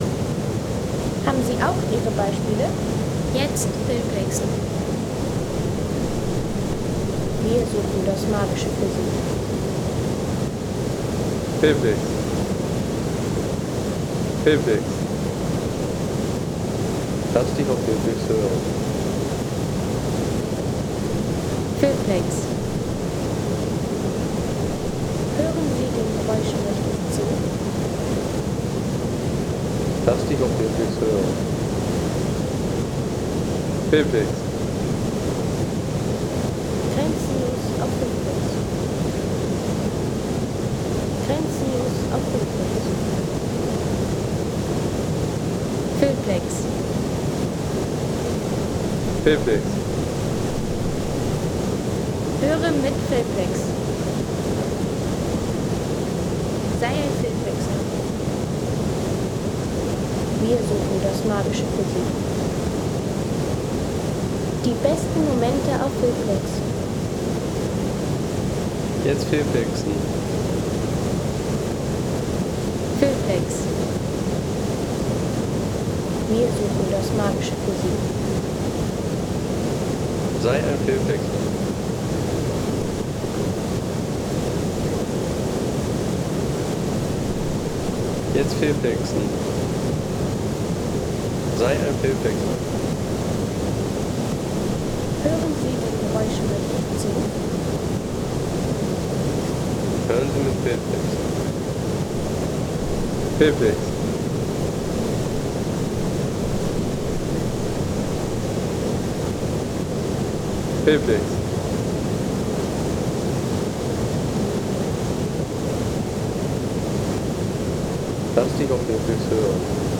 Der Fluss Torrente Anza
Der Fluss Torrente Anza Home Sounds Landschaft Flüsse Der Fluss Torrente Anza Seien Sie der Erste, der dieses Produkt bewertet Artikelnummer: 92 Kategorien: Landschaft - Flüsse Der Fluss Torrente Anza Lade Sound.... Der Fluss Torrente Anza in Staffa, Macugnaga.